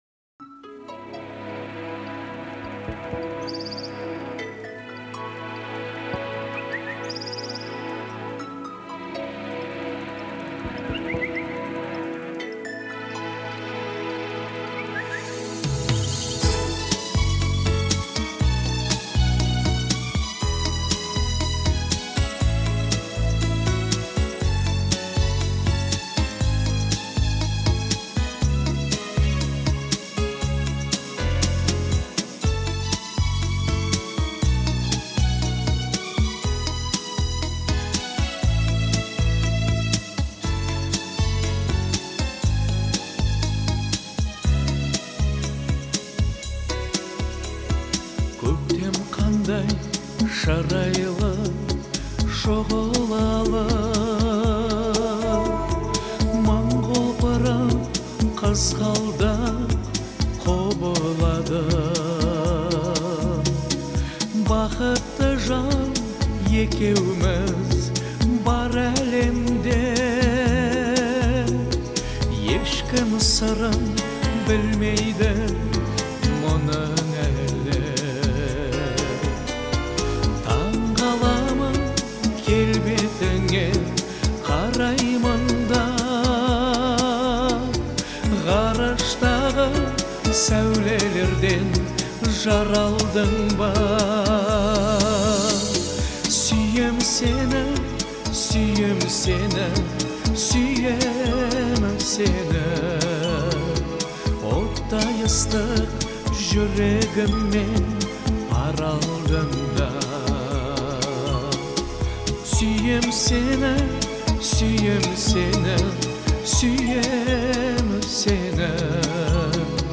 Звучание песни отличается мелодичностью и душевностью